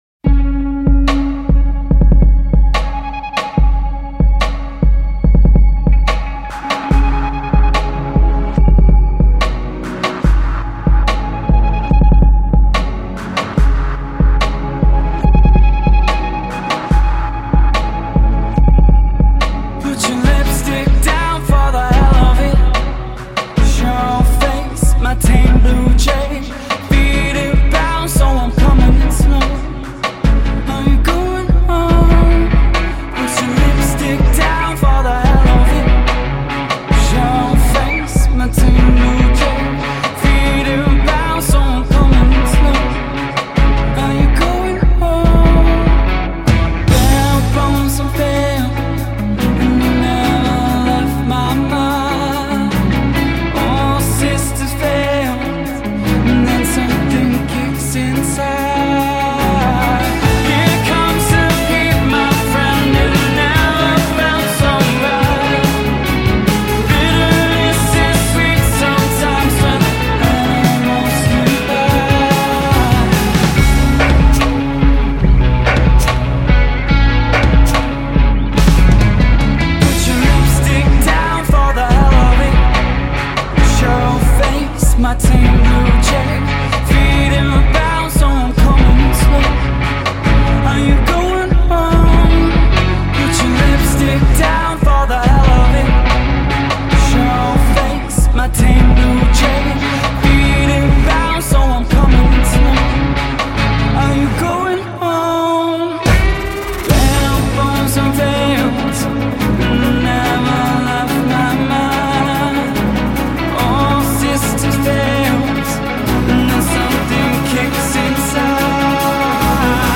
Australian six-piece
a sleek and lightweight samba